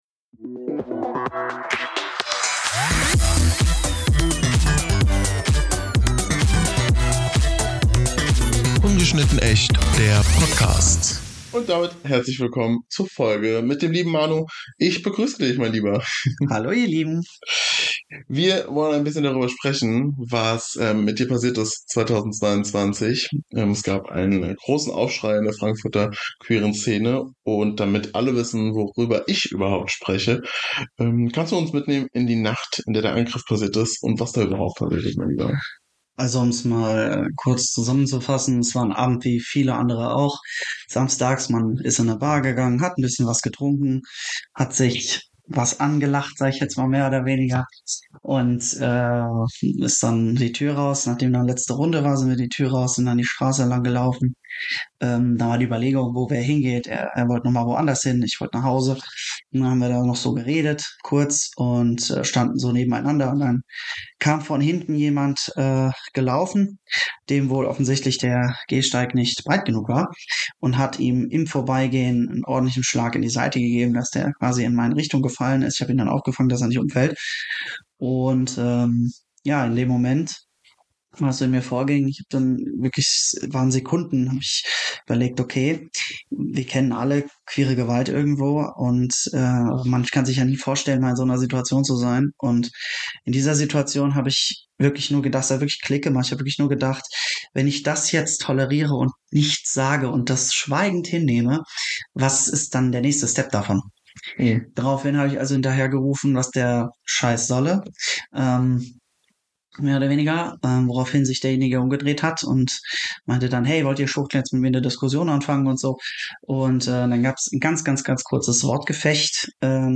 Es ist ein Gespräch über Trauma, Heilung, Wut, Mut und die Frage, wie man trotz allem weiterlebt, liebt und feiert.